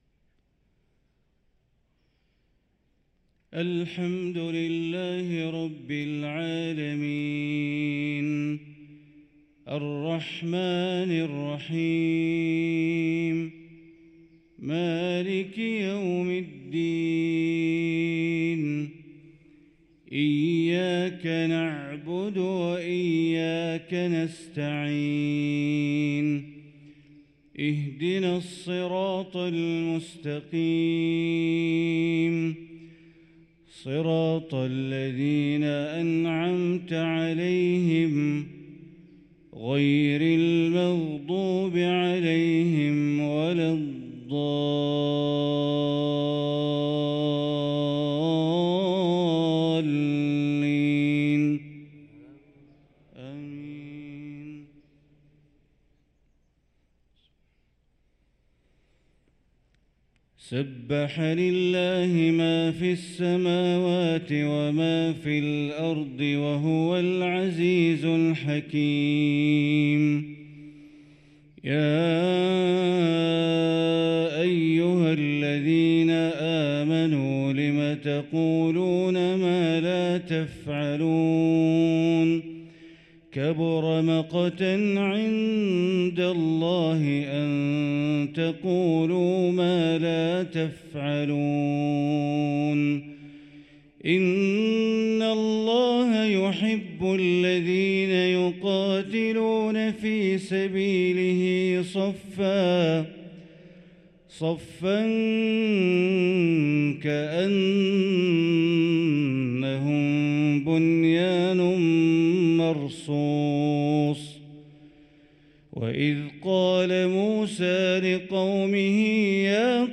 صلاة الفجر للقارئ بندر بليلة 8 ربيع الأول 1445 هـ
تِلَاوَات الْحَرَمَيْن .